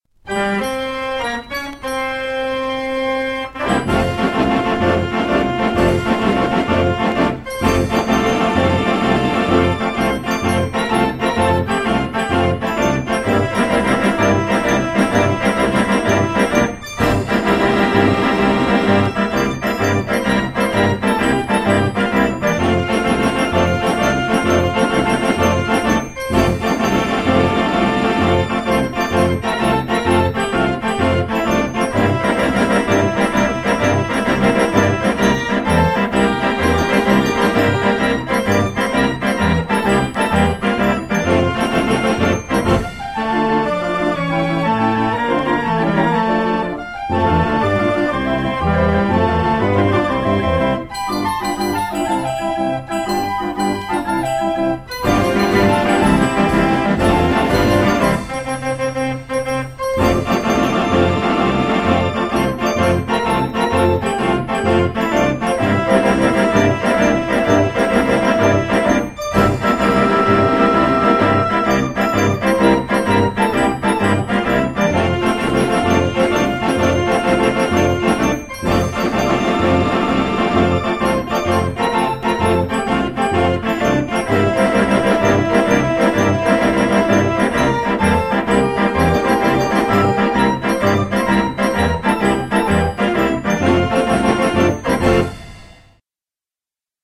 Historisches Drehorgeltreffen in Lichtensteig (SG)
Kleine Trommel
Grosse Trommel/Becken